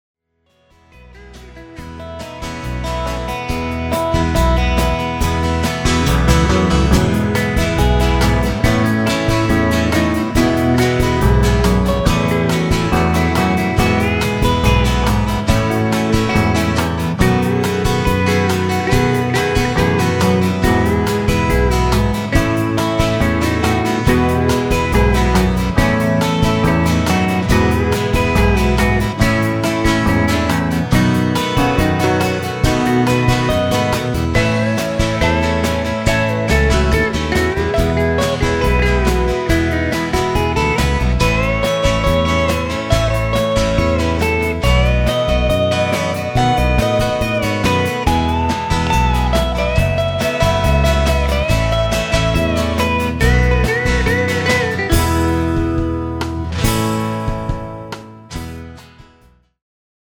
BASS GUITAR BY EAR